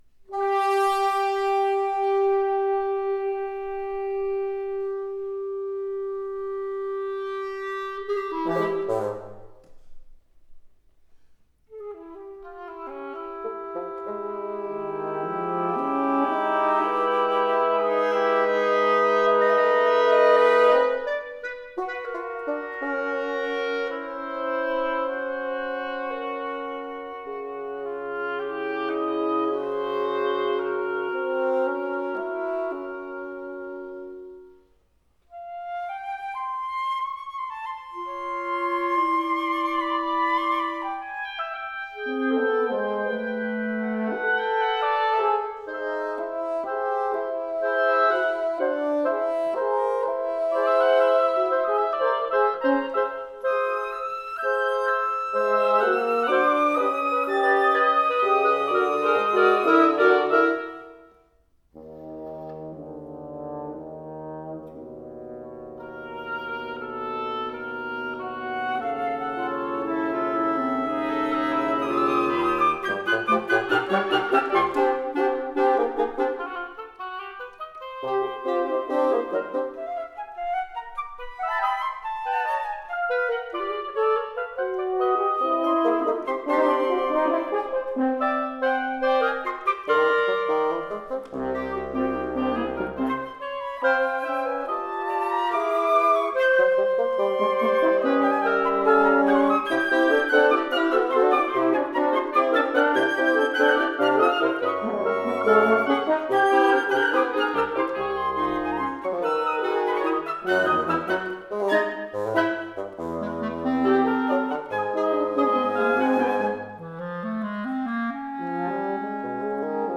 I. Lugubre; Allegro ritmico (6:55)
Flute
Oboe
Bb Clarinet
F Horn
Bassoon